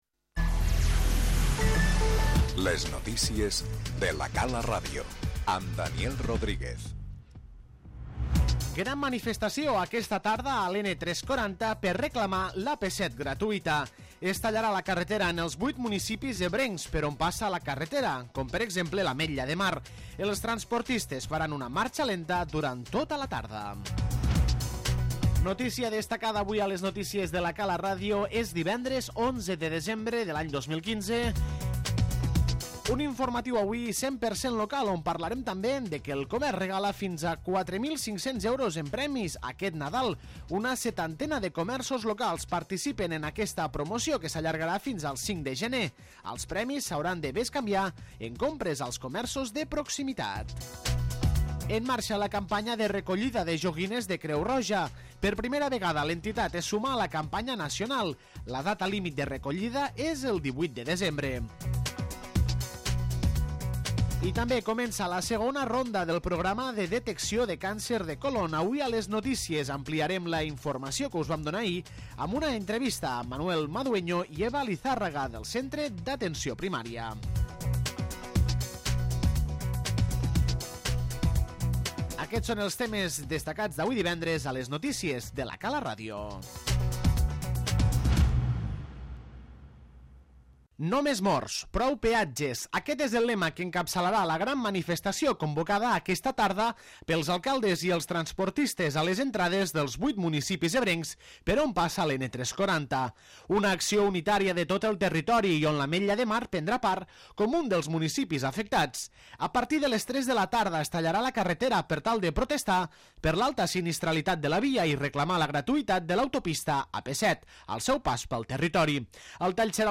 Encetem l'informatiu d'avui amb la Gran manifestació aquesta tarda a l'N-340 per reclamar l'AP-7 gratuïta i el Comerç Local regala fins a 4.500 euros en premis aquest Nadal.